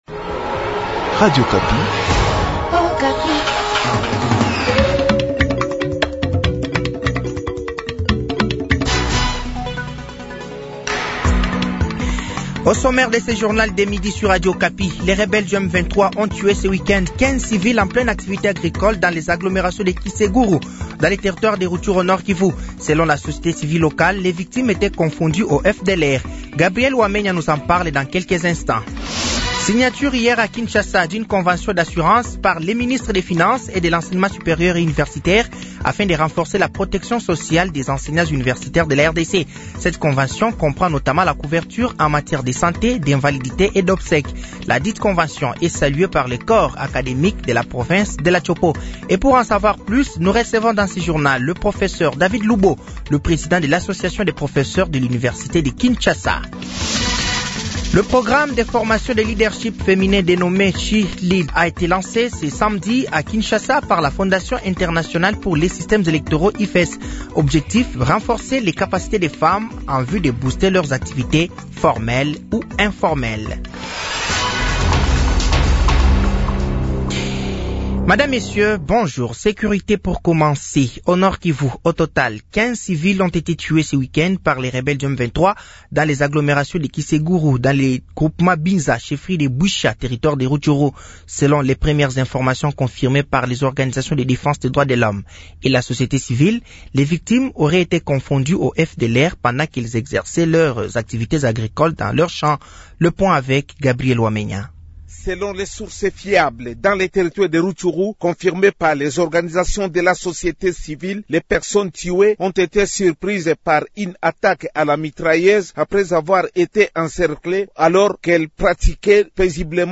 Journal français de 12h de ce dimanche 03 novembre 2024